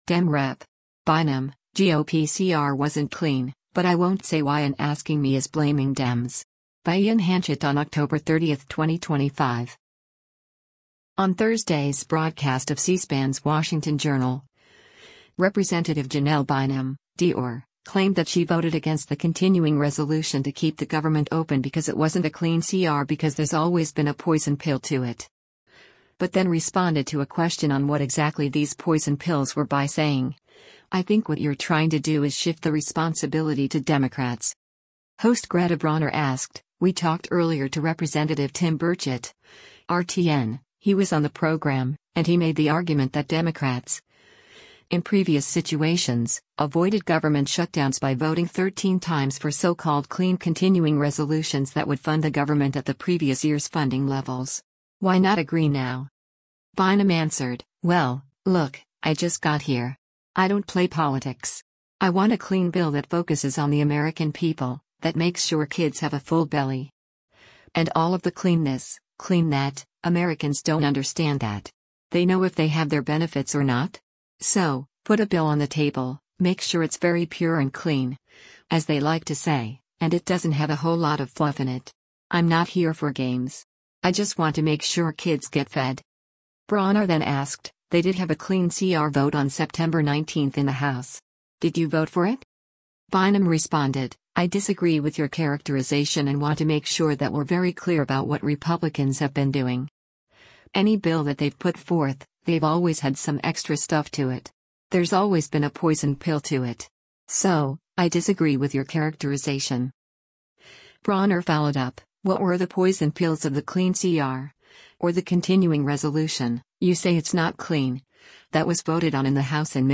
Video Source: C-SPAN
On Thursday’s broadcast of C-SPAN’s “Washington Journal,” Rep. Janelle Bynum (D-OR) claimed that she voted against the continuing resolution to keep the government open because it wasn’t a clean CR because “There’s always been a poison pill to it.”